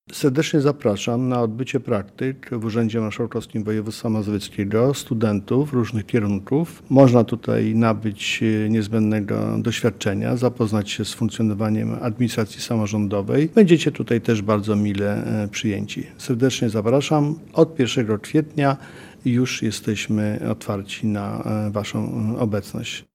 Udział w programie „Praktyki u Marszałka” to dobra okazja do zdobycia cennego doświadczenia zawodowego- zachęca Adam Struzik, marszałek woj. mazowieckiego: